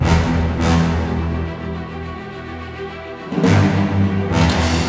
orchestra.wav